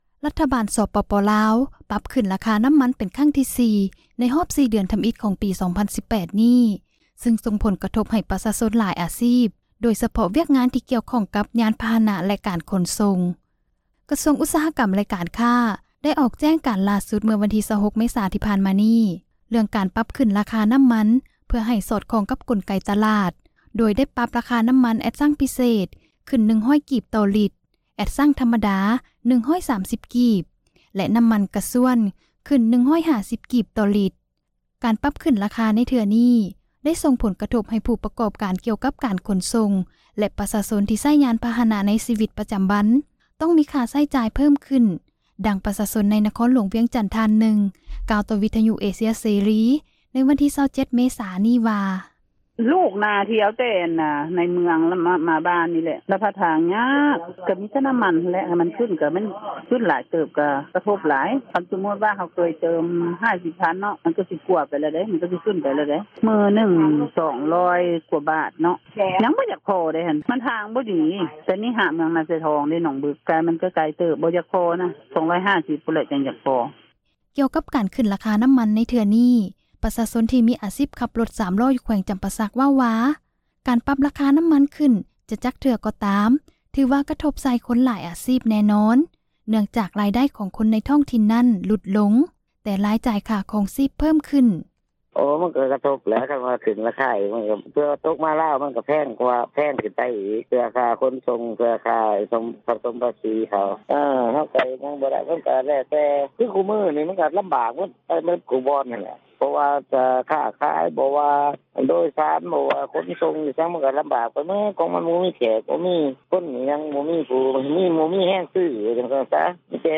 ການປັບຂຶ້ນຣາຄາໃນເທື່ອນີ້ ໄດ້ສົ່ງຜົລກະທົບ ໃຫ້ຜູ້ປະກອບການກ່ຽວກັບການຂົນສົ່ງ ແລະປະຊາຊົນ ທີ່ໃຊ້ຍານພາຫະນະ ໃນຊີວິດ ປະຈຳວັນ ຕ້ອງມີຄ່າໃຊ້ຈ່າຍເພີ້ມຂຶ້ນ, ດັ່ງປະຊາຊົນ ໃນນະຄອນຫຼວງວຽງຈັນ ທ່ານນຶ່ງ ກ່າວຕໍ່ເອເຊັຍເສຣີ ໃນວັນທີ 27 ເມສາ ນີ້ວ່າ: